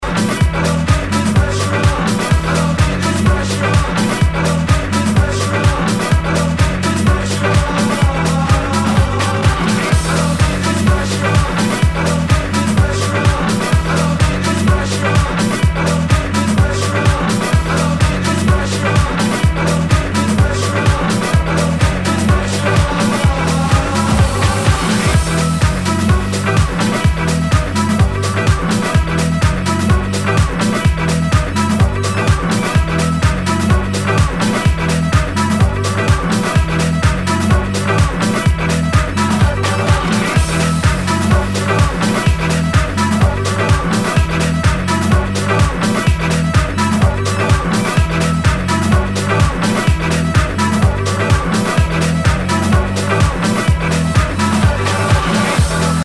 HOUSE/TECHNO/ELECTRO
ナイス！ファンキー・ハウス！